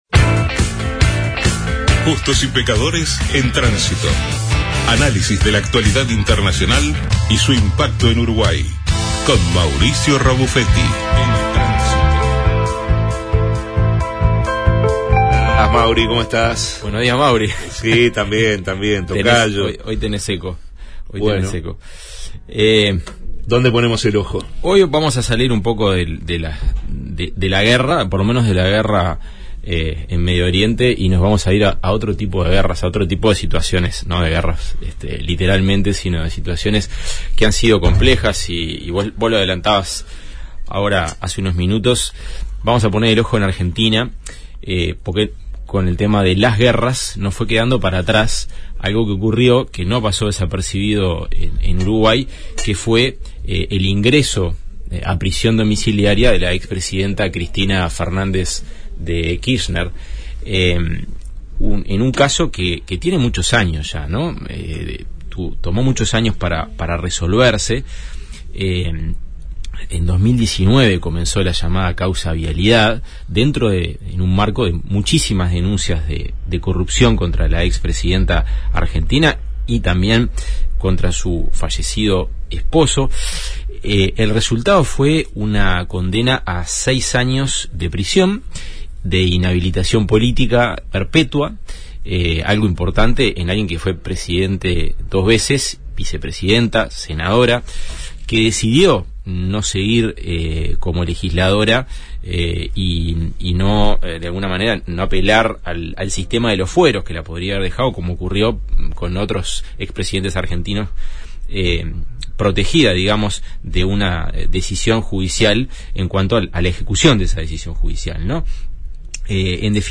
En tránsito, columna